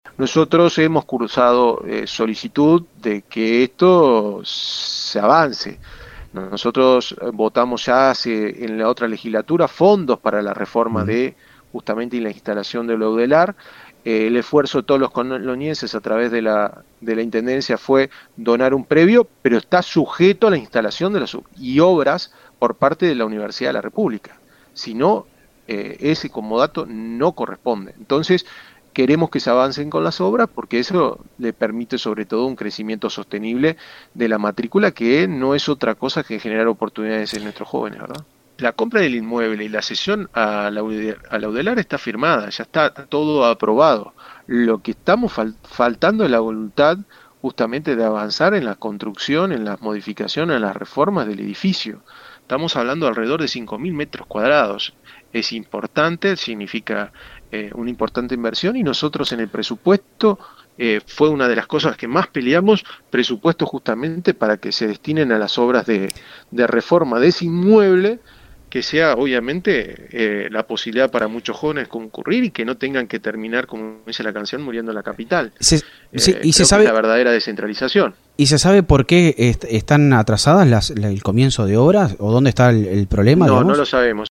Consultado en Radio del Oeste previo al encuentro, dijo que desconoce cuál es el motivo que demora el inicio de las obras en el predio adquirido por la Intendencia y donado luego a la Udelar, para que funcione la regional suroeste.Comentó que desde el lado de la Intendencia y la Junta Departamental ya está todo pronto.